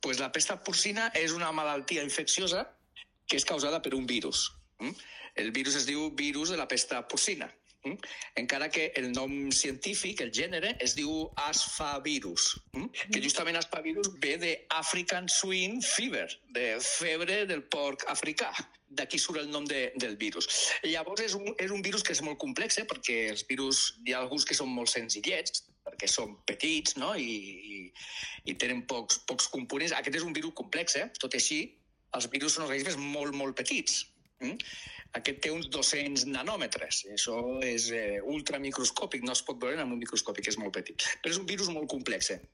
[REPORTATGE] La Pesta Porcina: què és i com ens afecta a les Terres de l’Ebre?